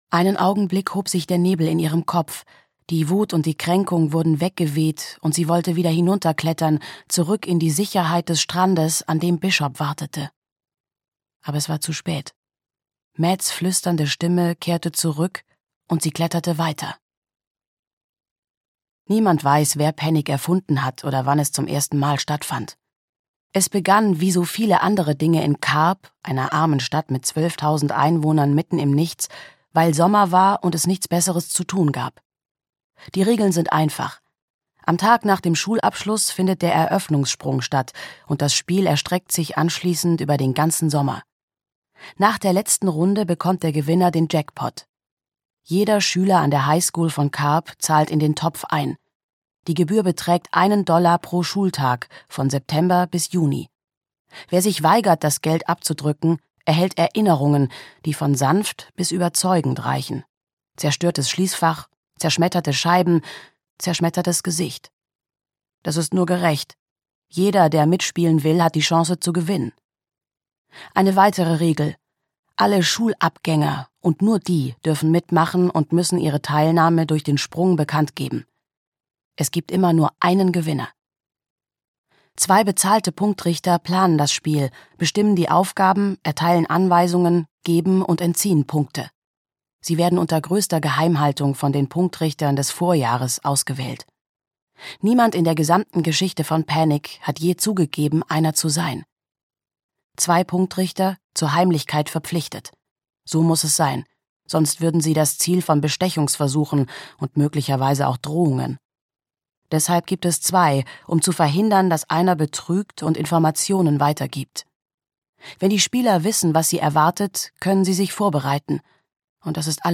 Panic - Wer Angst hat, ist raus - Lauren Oliver - Hörbuch